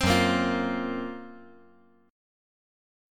Listen to F#M7b5 strummed